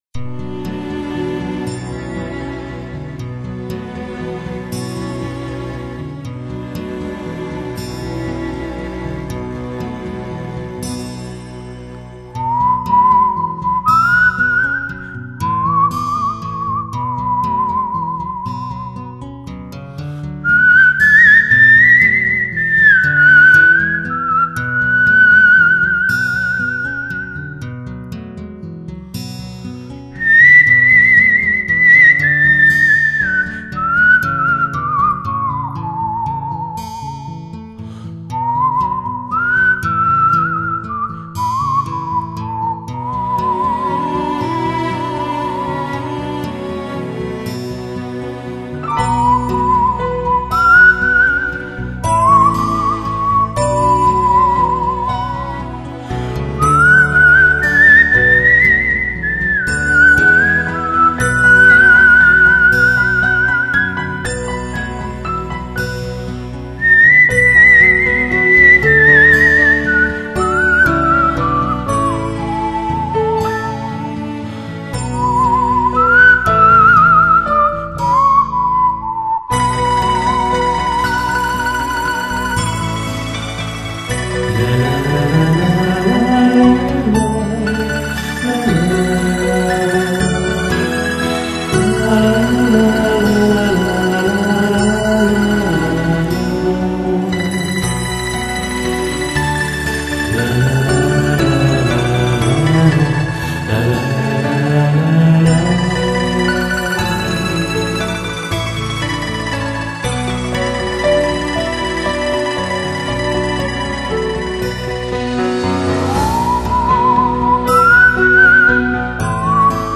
这辑音乐中充满古色古香的宁静，没有普罗大众似的浮躁，只有一种恰似天籁的共鸣。
口哨